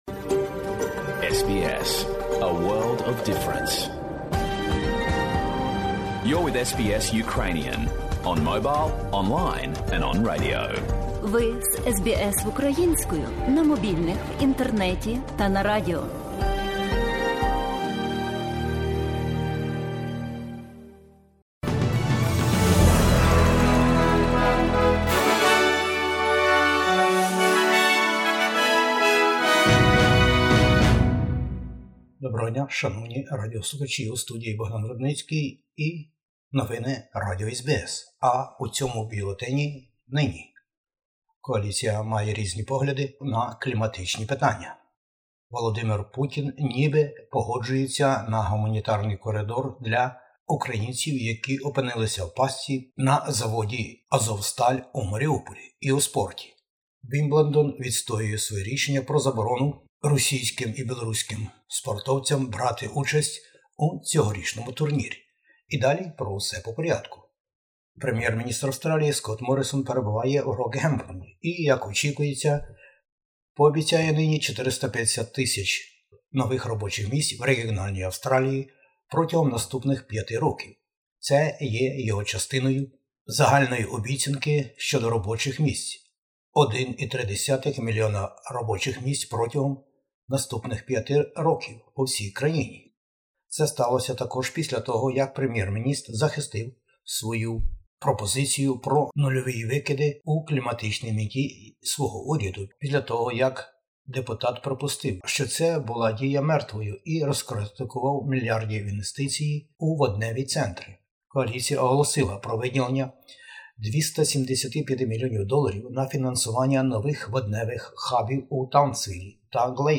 Бюлетень SBS новин українською мовою. Федеральні вибори-2022 в Австралії. Кліматичні питання і навколо них у передвиборчих кампаніях. ООН і США про війну в Україні та право на можливе членство України у НАТО.